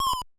dash.wav